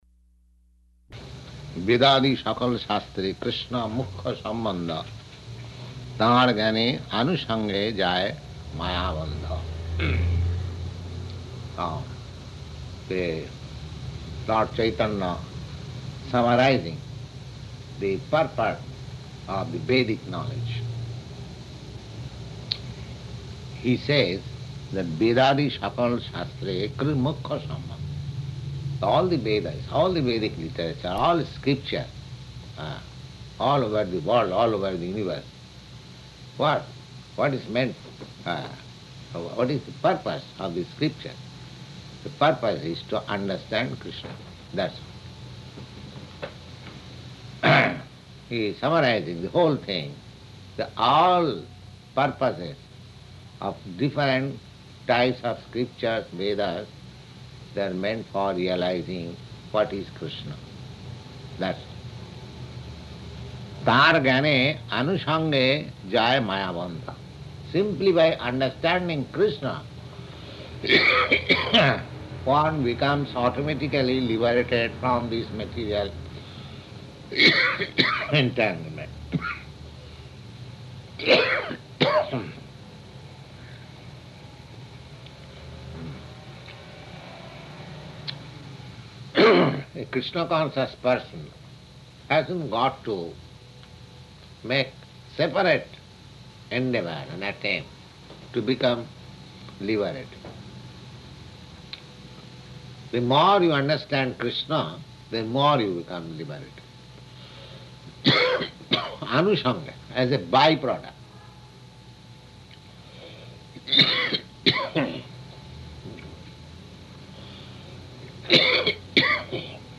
Location: New York
[coughing] [referring to coughing:] Just see how material entanglement, this body.